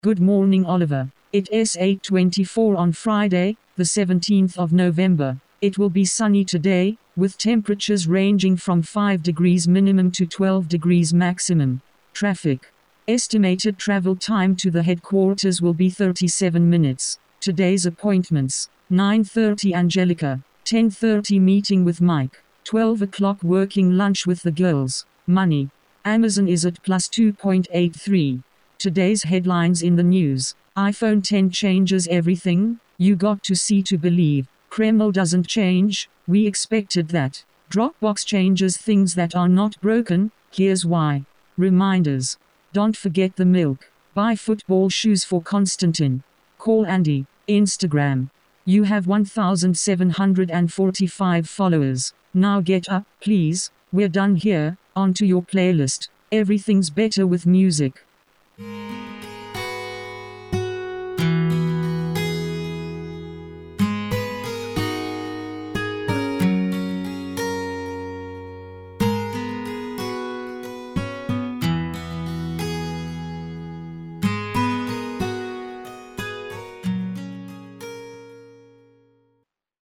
Talking Alarm Clock
TalkClok uses your device’s built in voices.
iOS built in